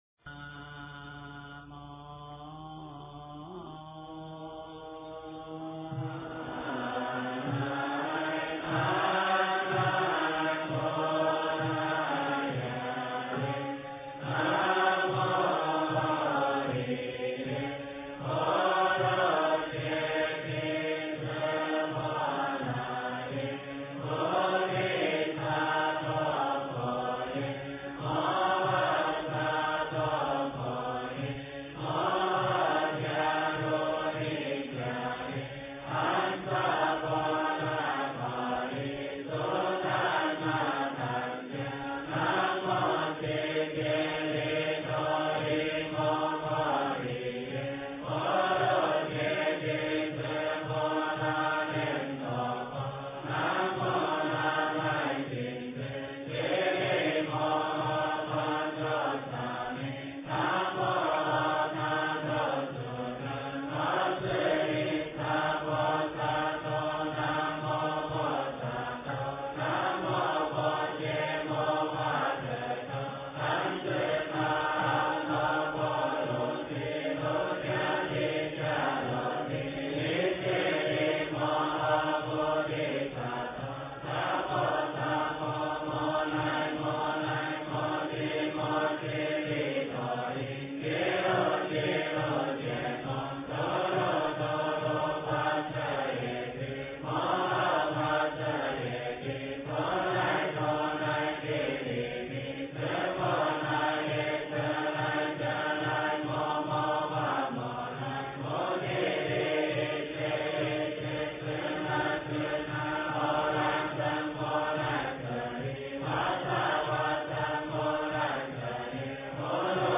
大悲咒--僧团 经忏 大悲咒--僧团 点我： 标签: 佛音 经忏 佛教音乐 返回列表 上一篇： 八十八佛大忏悔文-三皈依--僧团 下一篇： 三归依--僧团 相关文章 八十八佛大忏悔文--净土Pure Land 八十八佛大忏悔文--净土Pure Land...